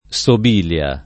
Sobilia [ S ob & l L a ]